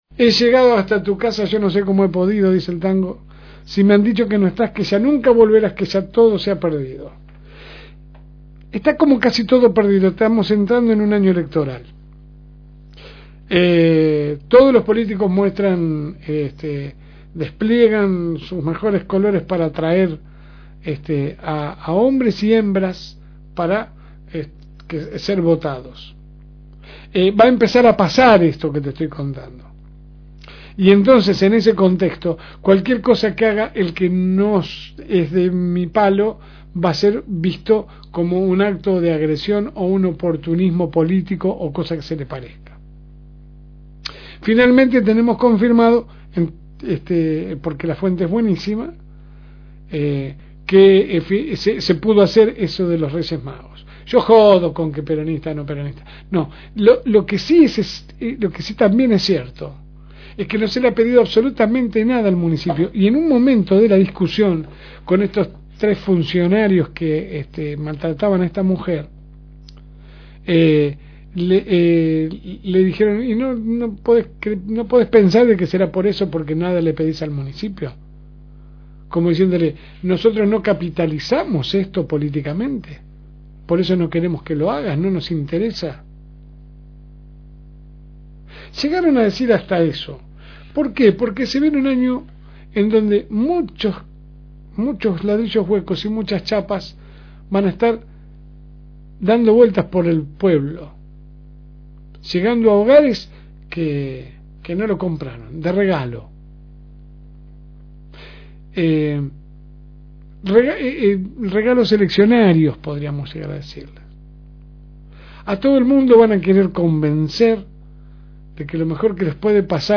AUDIO – Editorial de LSM